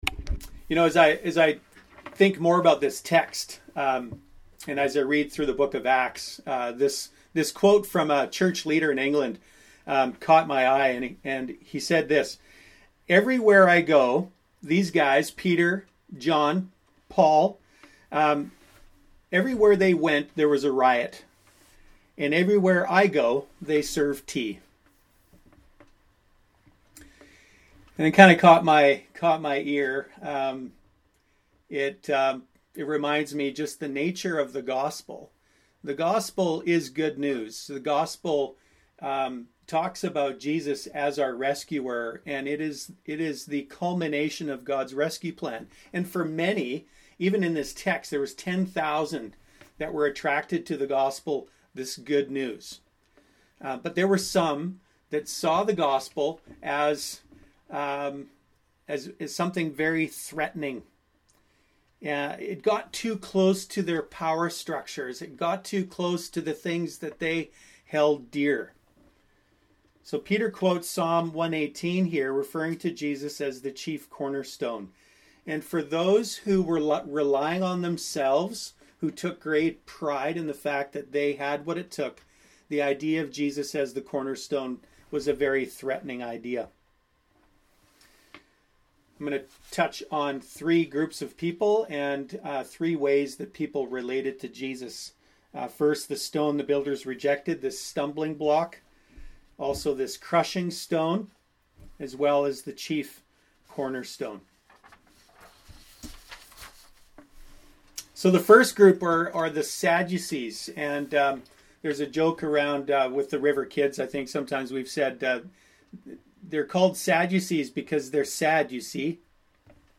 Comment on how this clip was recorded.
The message is actually shorter but there were some Q&A throughout, which you are free to FF or listen to the whole thing.